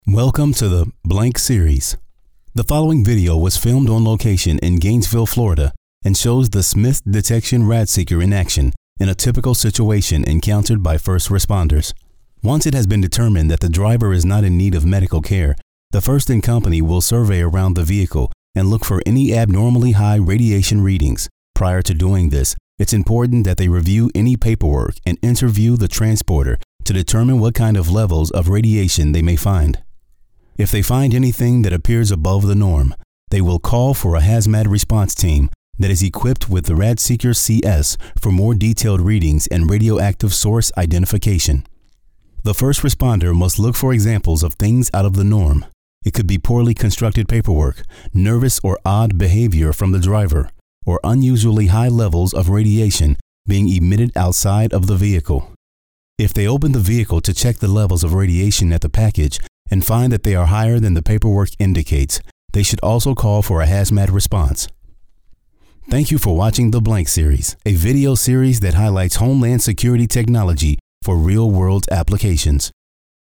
Smooth Bassy Voice Very articulate, Very Clear!
Sprechprobe: Industrie (Muttersprache):